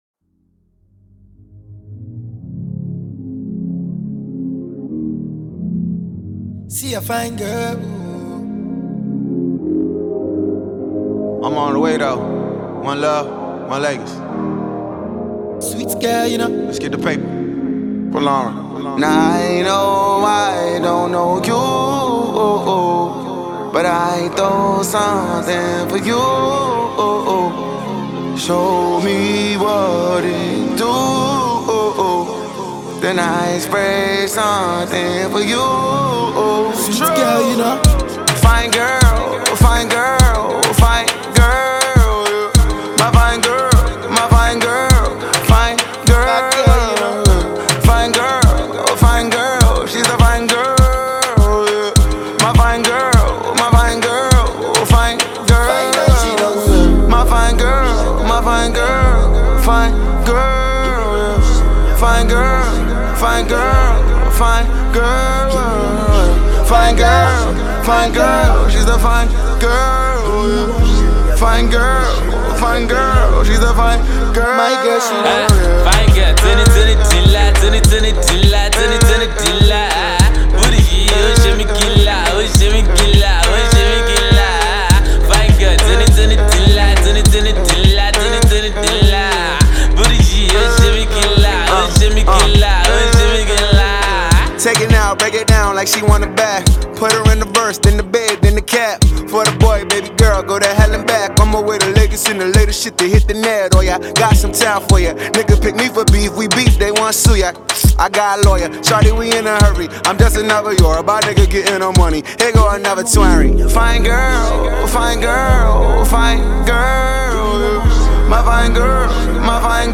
American born rapper